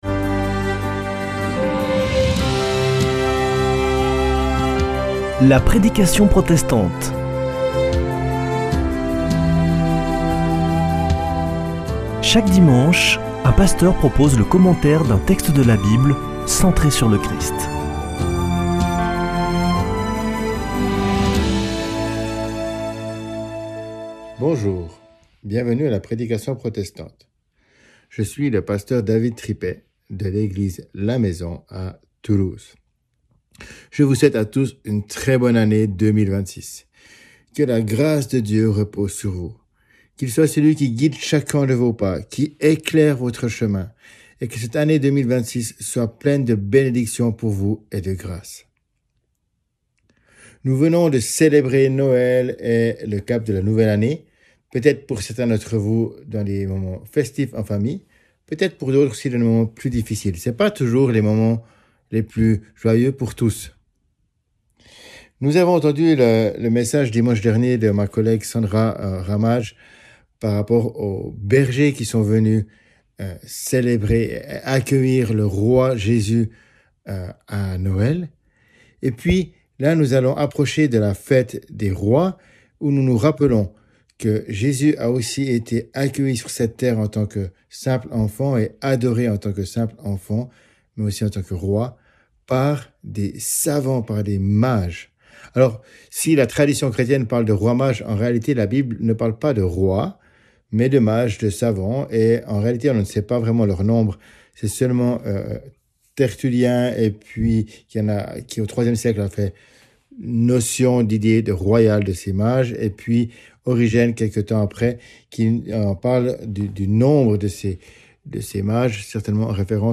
Il nous encourage à chercher Dieu qui veut nous révéler sa gloire, guide nos pas durant toute l'année. Au travers de cette prédication, vous entendrez aussi des témoignages de guérisons miraculeuses.